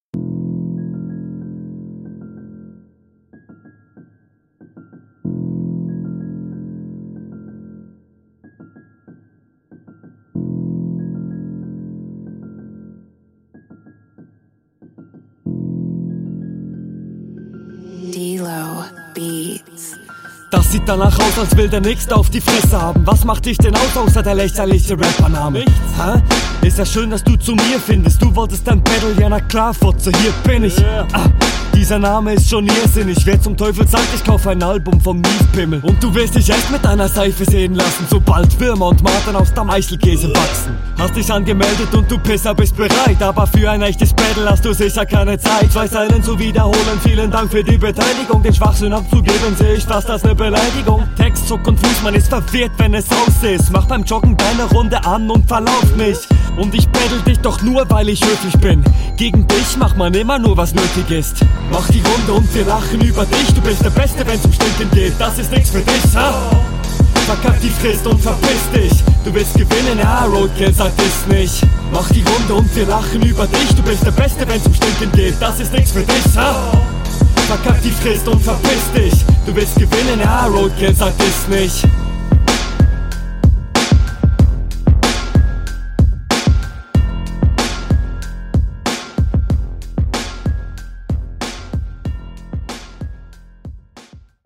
Ooh, der aggressive D-Low Beat mmmmmmm.
kommst ganz cool auf den beat. style wirkt noch nicht ganz ausgereift aber du deliverst …